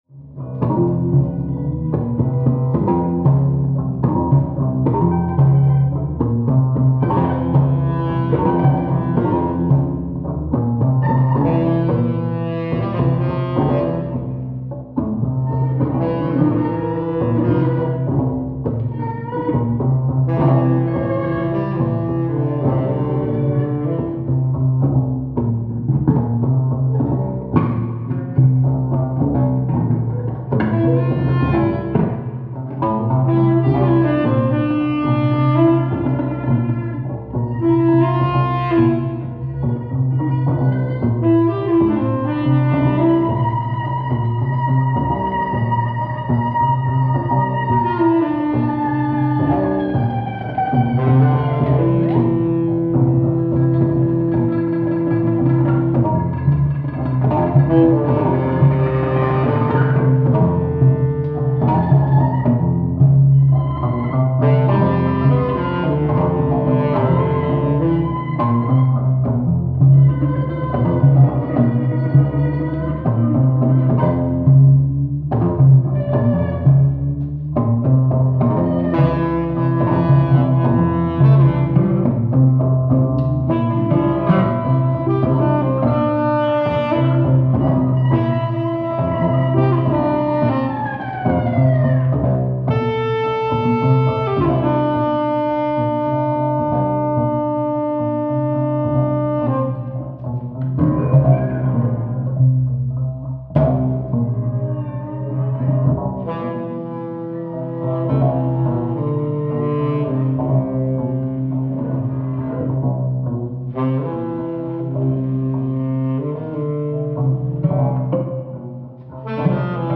on prepared guitar
synth and percussive contributions